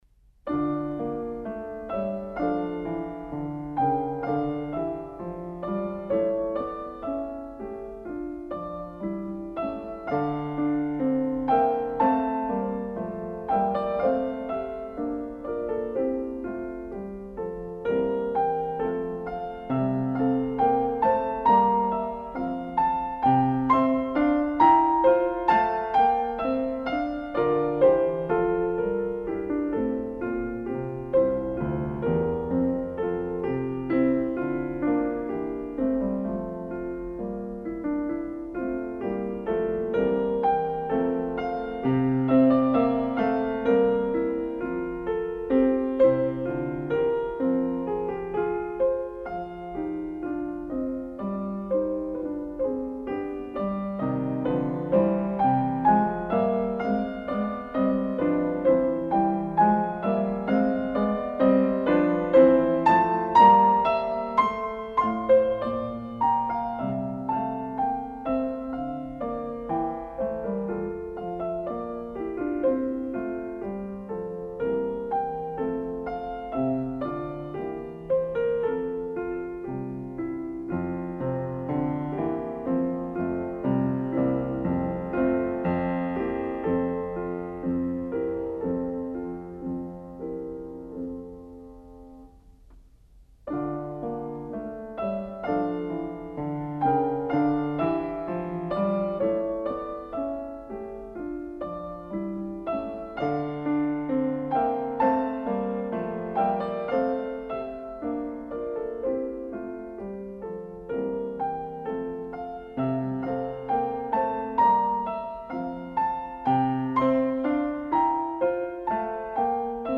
Сонаты для фортепиано.